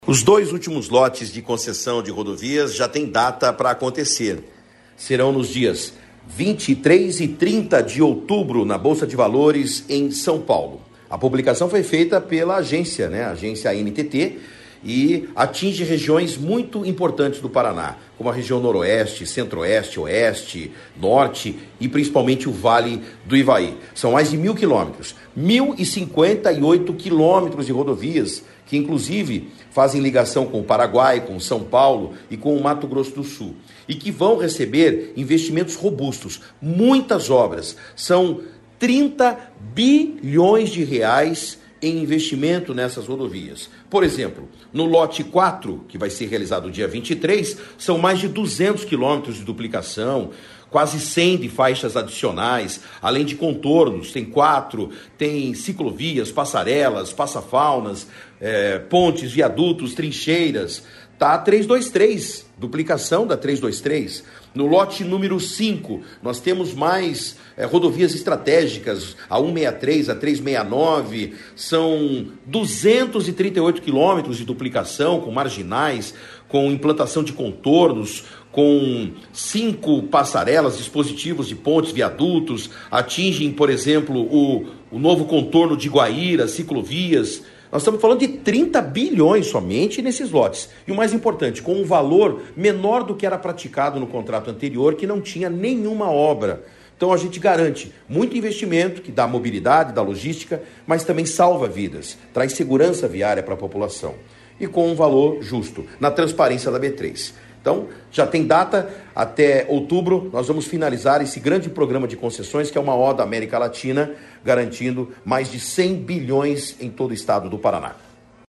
Sonora do secretário Estadual de Infraestrutura e Logística, Sandro Alex, sobre a marcação de datas para os leilões dos Lotes 4 e 5 das concessões rodoviárias do Paraná